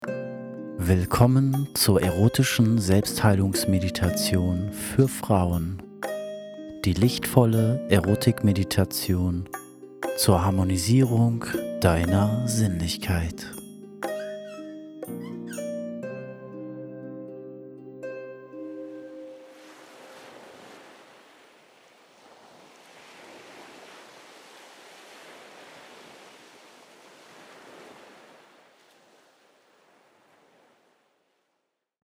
Erotische Selbstheilungsmeditation für Frauen
Trailer-Erotische-Meditation.mp3